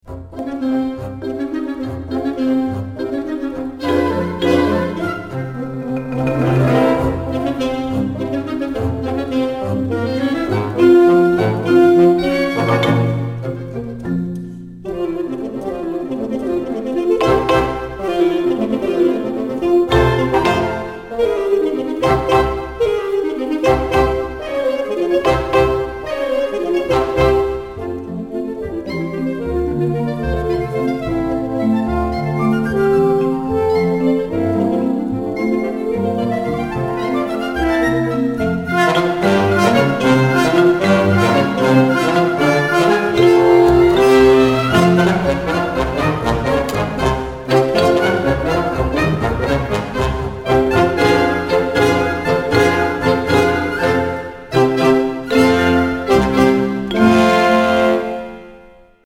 Waltz from Macbeth – Guiseppe Verdi – SWE – Audio sample